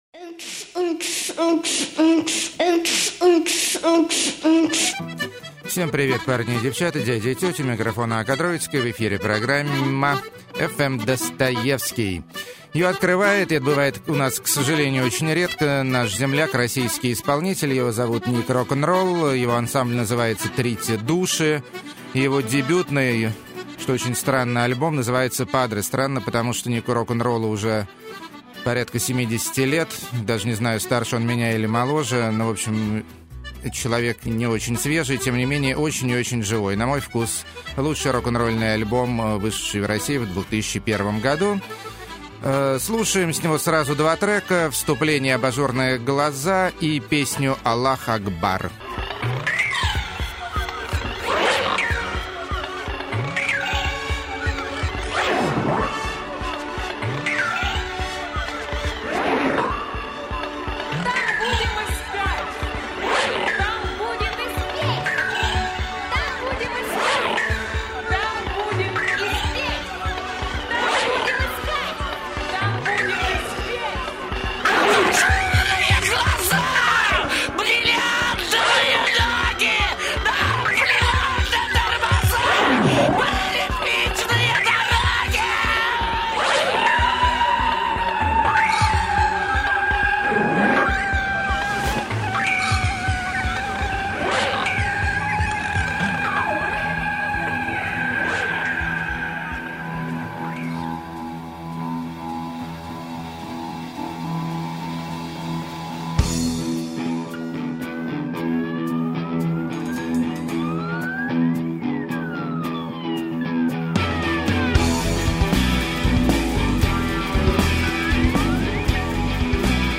Блистательный Авант-поп
Смачный Лягушачий Downtempo.
Темноватый Театральный Щансон.
Талантливый Нойз-фолк.] 10.
Мощная Этно-вокалистка.